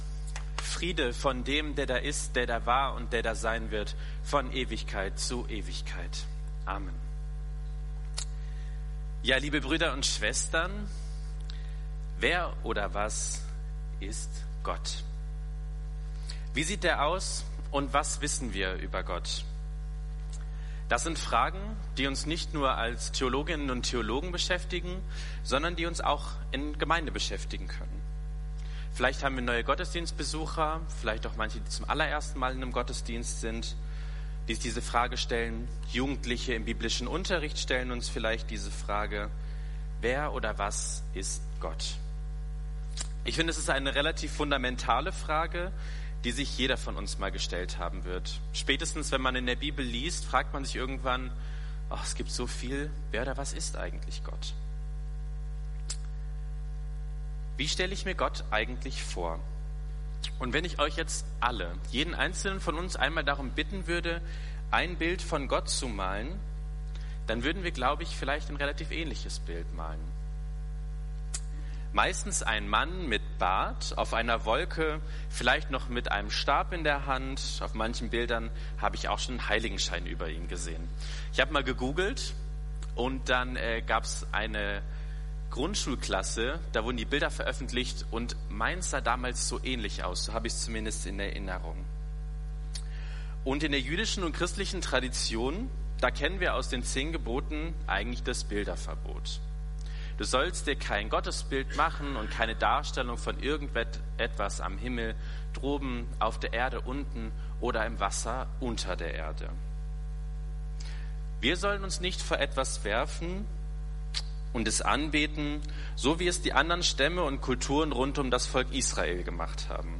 Predigt Podcast FeG Wuppertal Vohwinkel
Predigt vom 11.05.2025